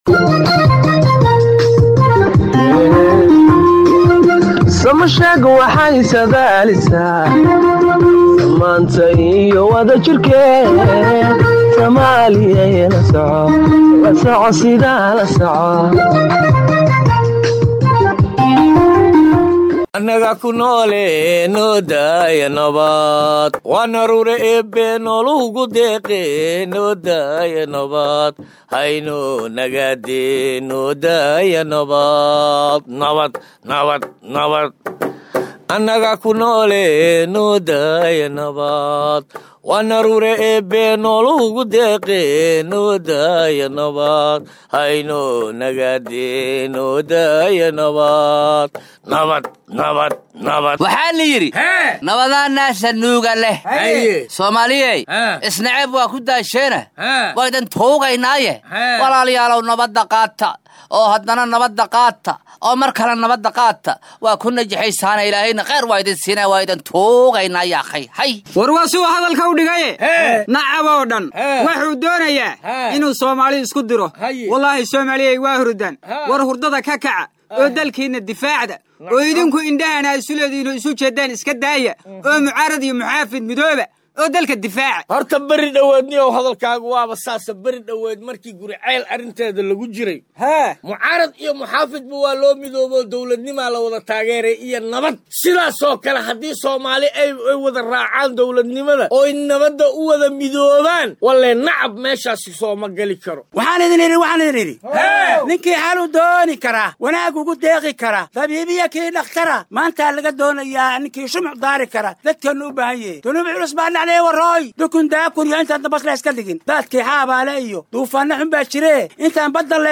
Dhageyso riwaayadda Sama Sheeg 13.11.2021